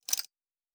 Metal Tools 05.wav